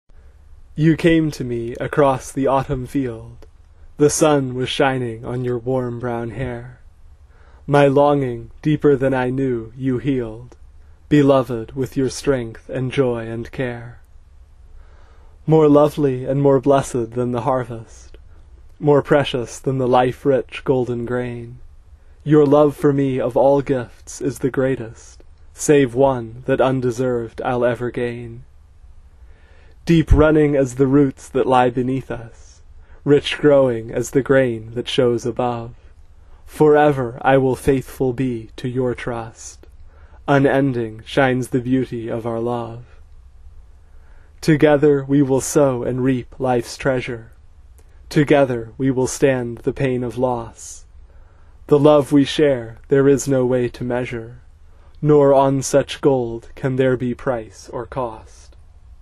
They really should be sung, but I... uh... have a problem with carrying tunes, so for now you will have to settle for the songs read aloud as poems.